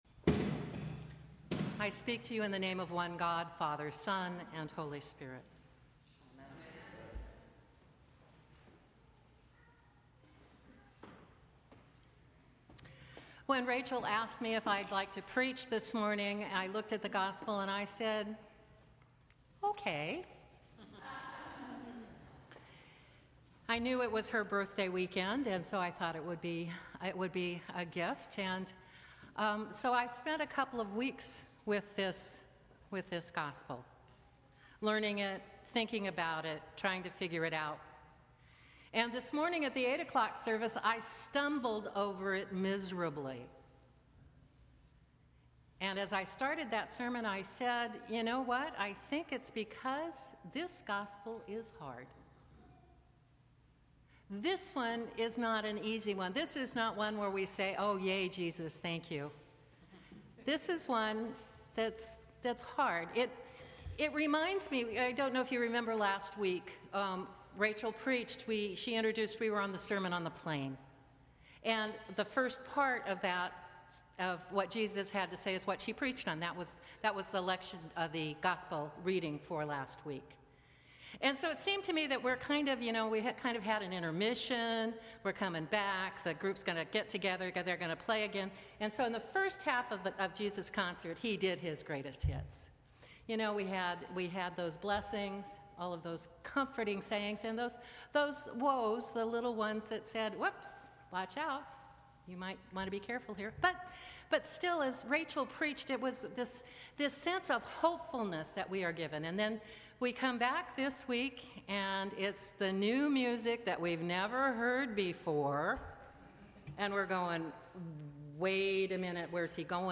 Sermons from St. Cross Episcopal Church Seventh Sunday after the Epiphany Feb 23 2025 | 00:14:11 1x 00:00 / 00:14:11 Subscribe Share